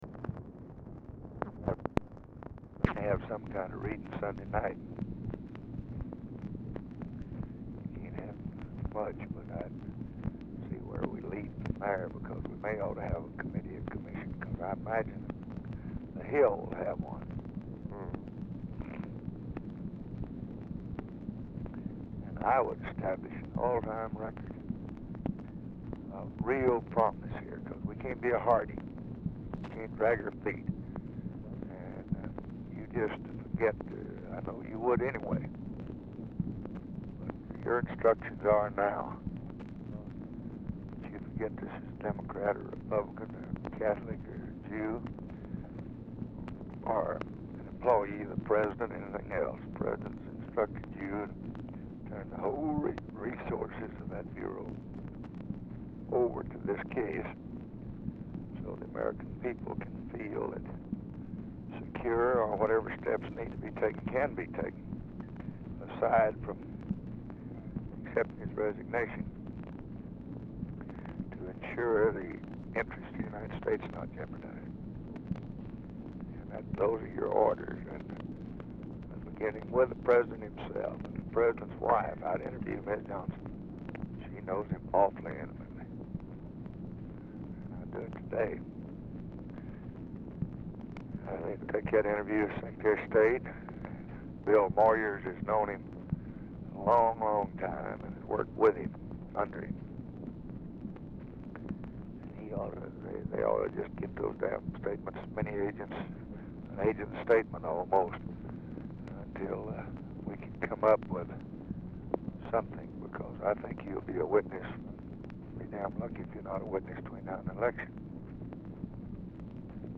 Telephone conversation # 5893, sound recording, LBJ and NICHOLAS KATZENBACH, 10/15/1964, 7:26AM | Discover LBJ